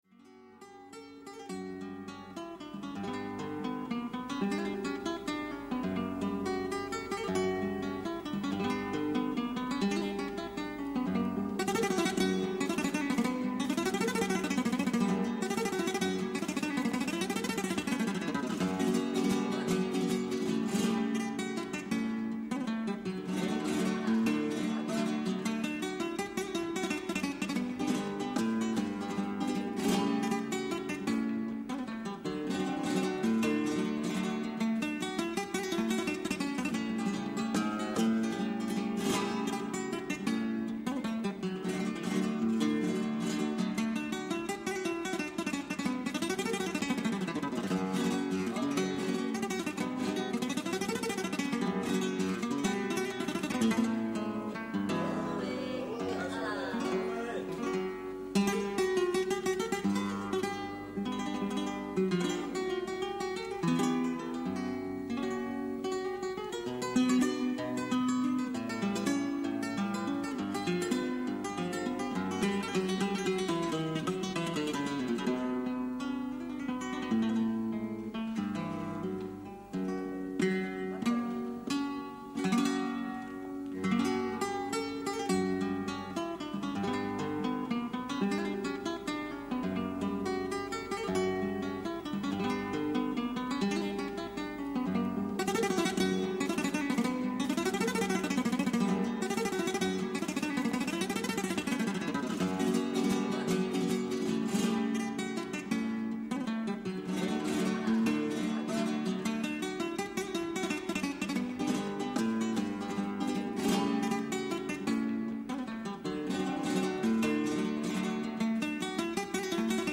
guitarra-vol-bajo-corto.mp3